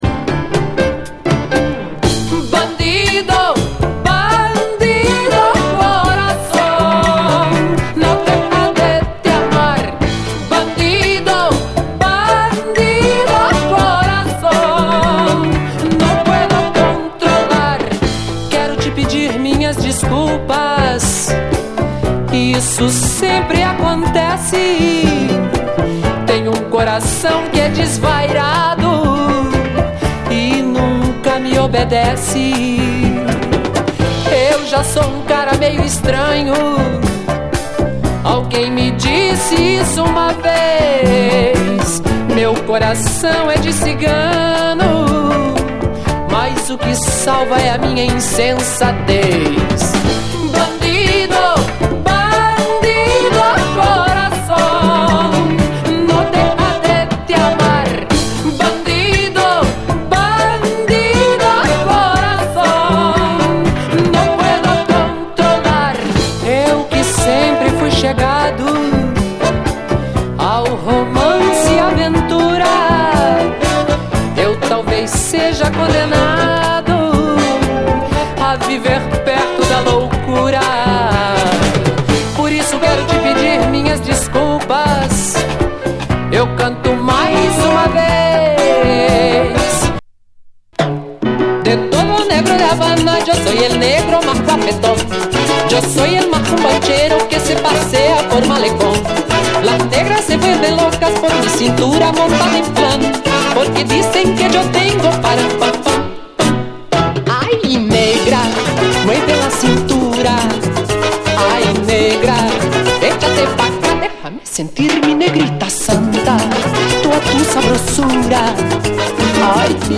BRAZIL
エモーショナルなブラジリアン・ソウル・チューン
ハッピーに沸かせるサンバ・ソウル
哀愁系サンバ・ソウル